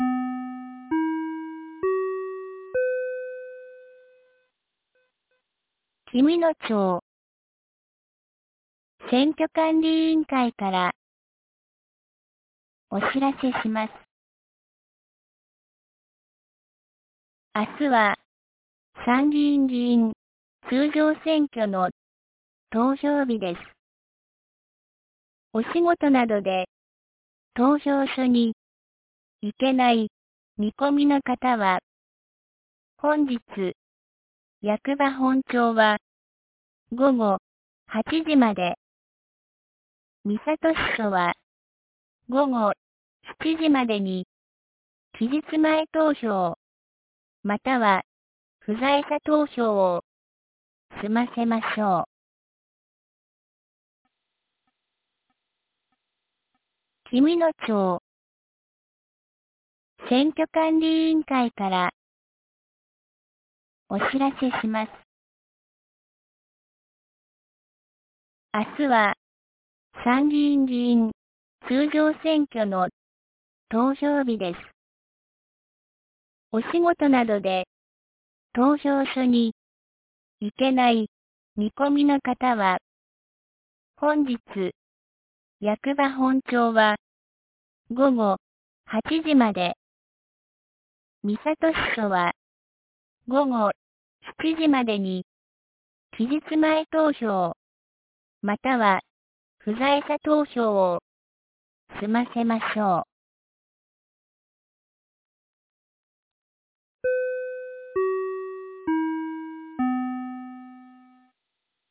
2025年07月19日 17時07分に、紀美野町より全地区へ放送がありました。